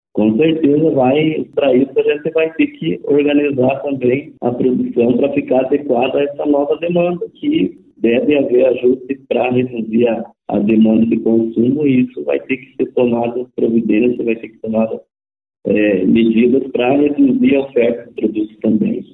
SONORA-DEMITE-TARIFA-BO.mp3